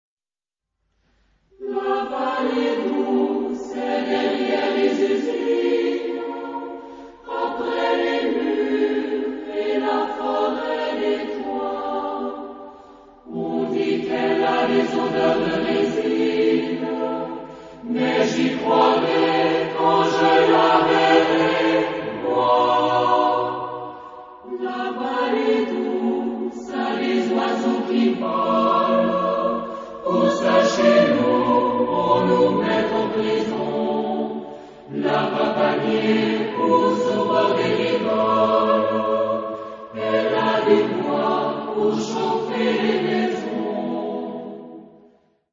Genre-Style-Form: Secular ; Poem
Mood of the piece: descriptive ; sombre
Type of Choir: SATB  (4 mixed voices )
Tonality: G tonal center